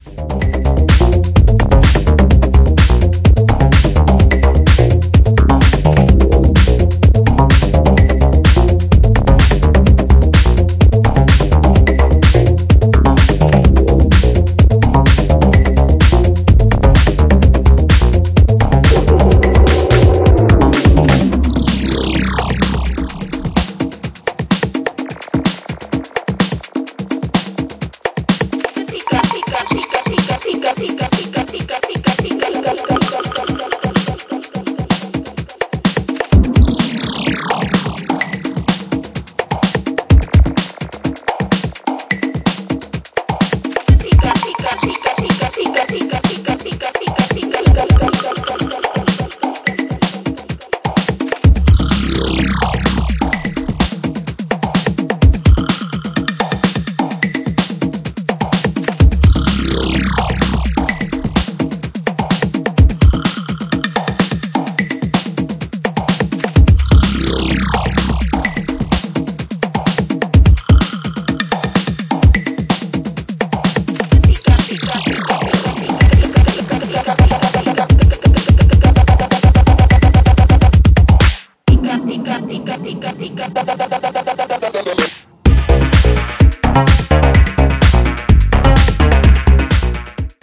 ジャンル(スタイル) HOUSE / TECHNO